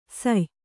♪ say